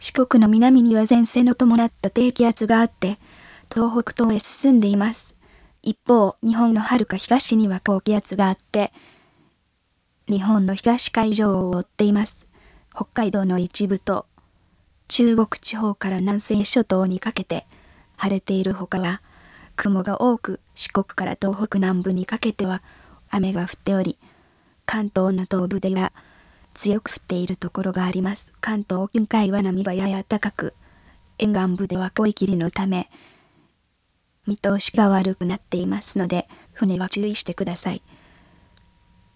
Chatr: a synthesis system